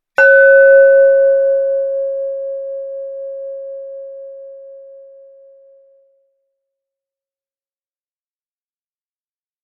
bell ding glass gong lid percussion ring sound effect free sound royalty free Sound Effects